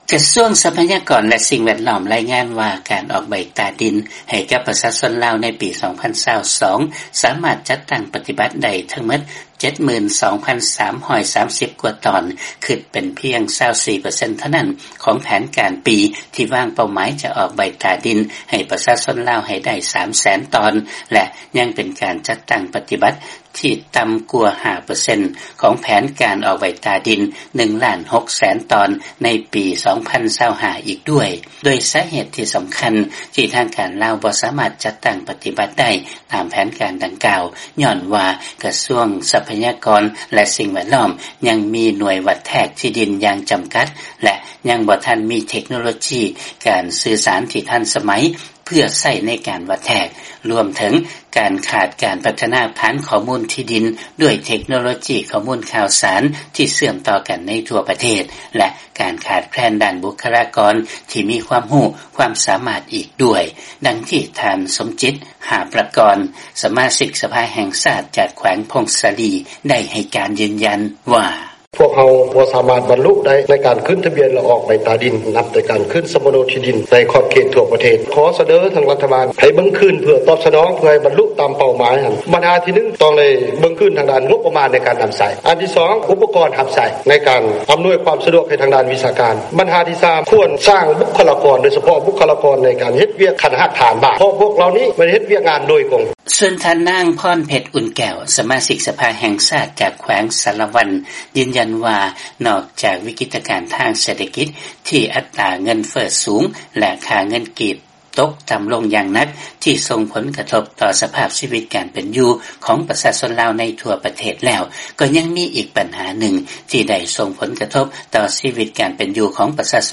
ຟັງລາຍງານ ທາງການລາວ ວາງແຜນການອອກໃບຕາດິນໃຫ້ກັບປະຊາຊົນໃຫ້ໄດ້ 1.6 ລ້ານຕອນພາຍໃນປີ 2025